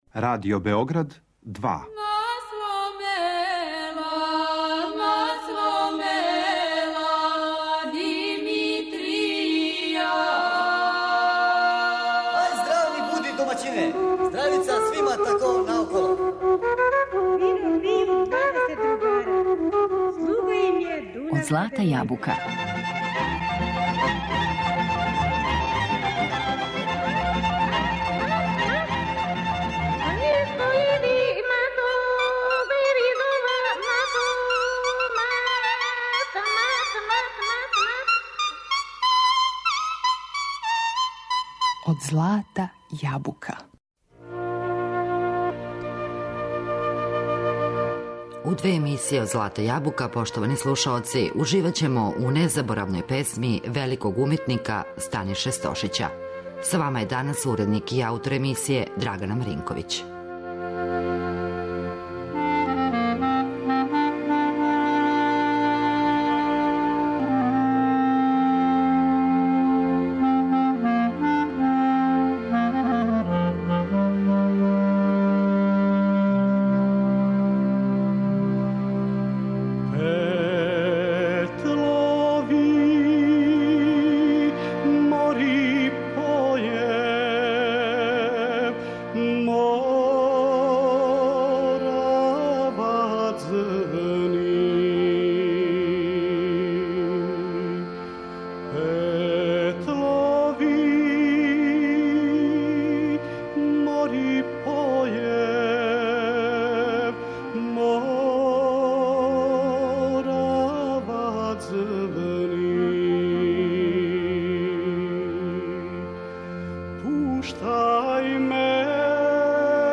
Живот је посветио завичајним песмама.